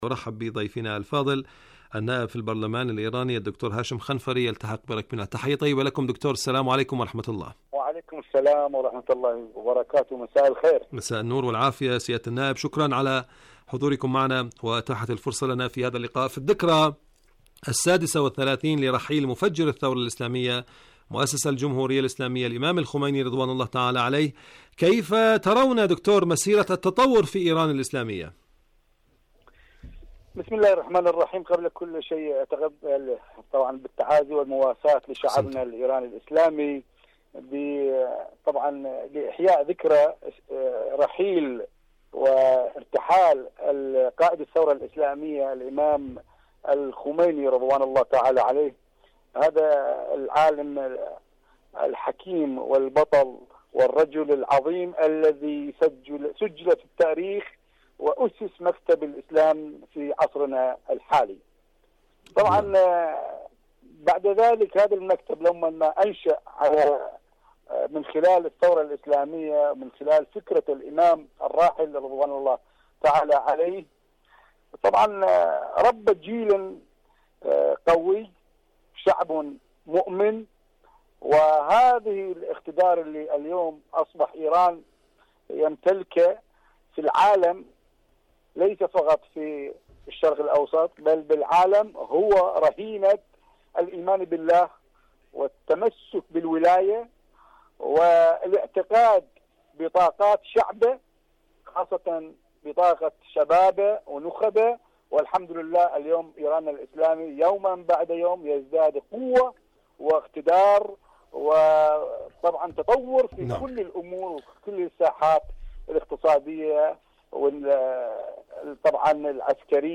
إذاعة طهران- حدث وحوار: مقابلة إذاعية مع الدكتور هاشم خنفري النائب في البرلمان الإيراني من إيران حول موضوع في رحيل النور.